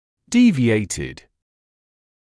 [‘diːvɪeɪtɪd][‘диːвиэйтид]искривленный, смещённый